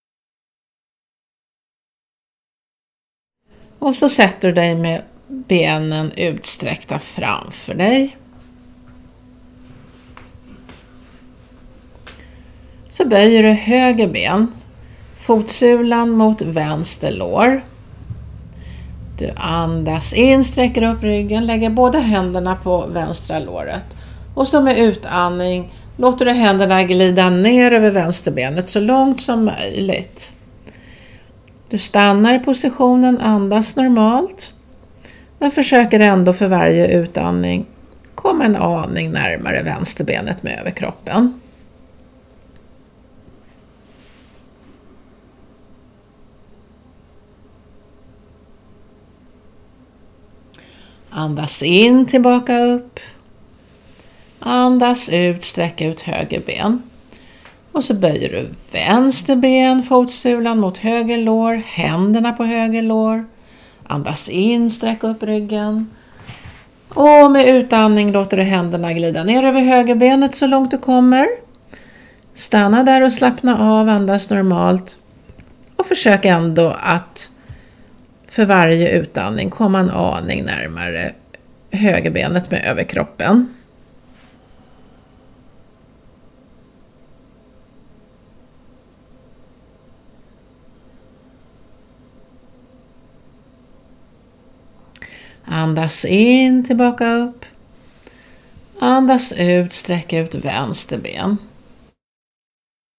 Genre: Yoga.